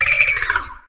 Cri de Froussardine dans sa forme Solitaire dans Pokémon Soleil et Lune.